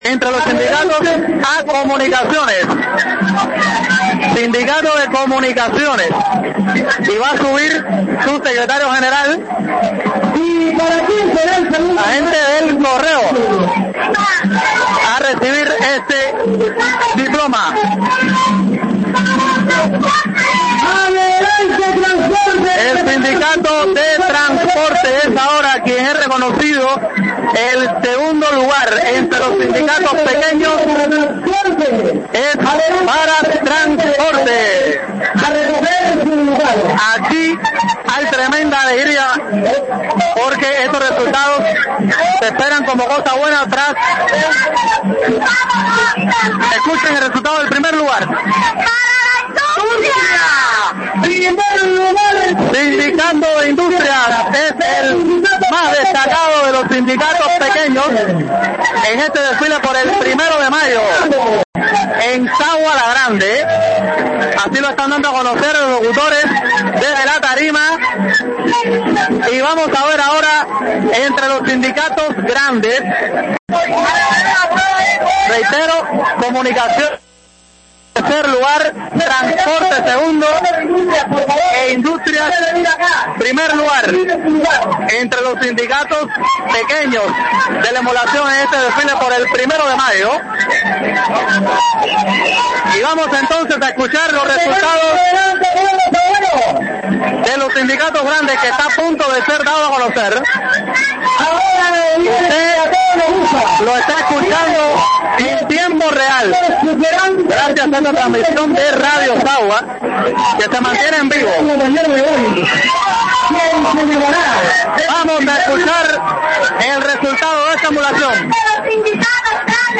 📻 Escuche En Vivo gracias a la transmisión de 🎤 Radio Sagua los ganadores de la emulación del Primero de Mayo en Sagua la Grande